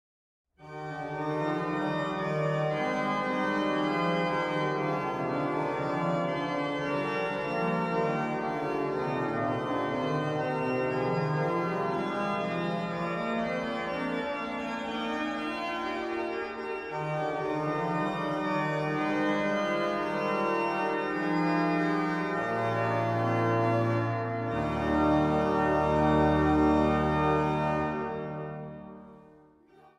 vleugel en orgel.
Zang | Kinderkoor